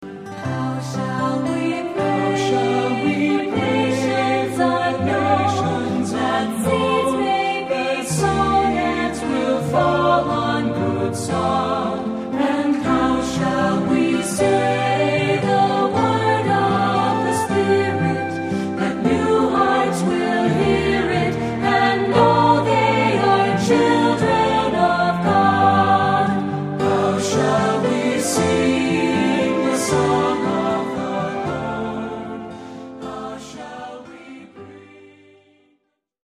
Choir Accompaniment Track